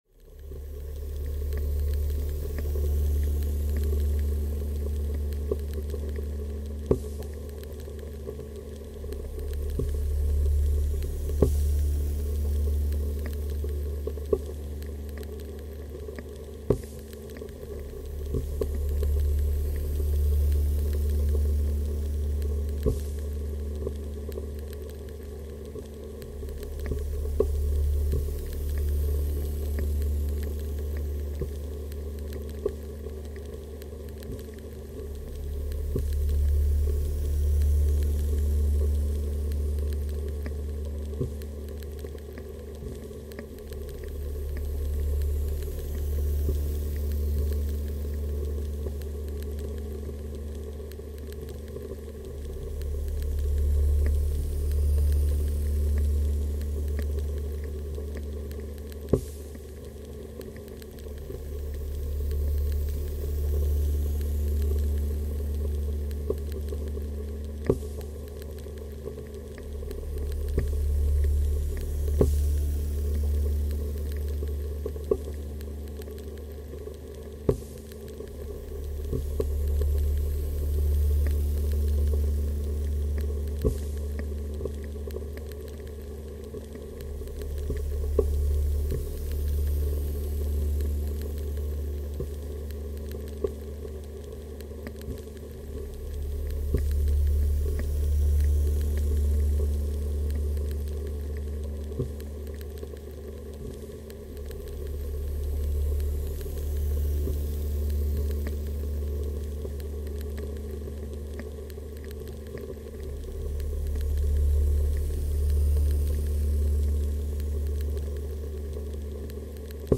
صدای خنک کننده گوشی برای اندروید